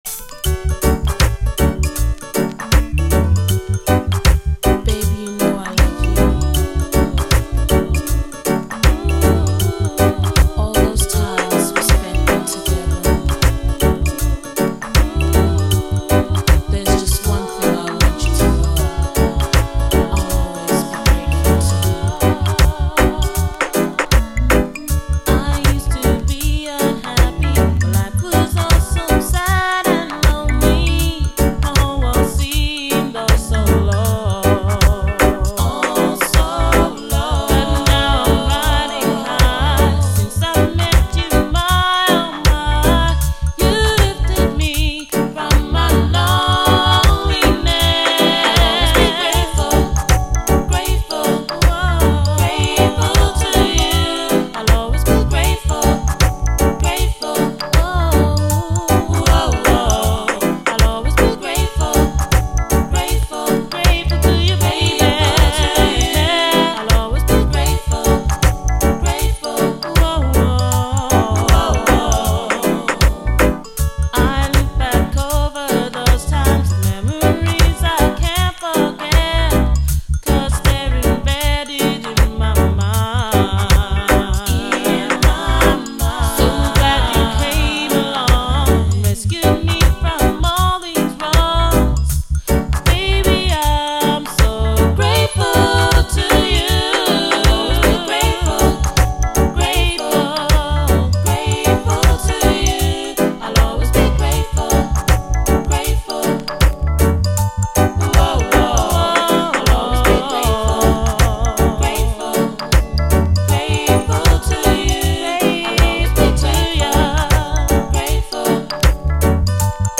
REGGAE
マイナー90’SフィメールUKラヴァーズ！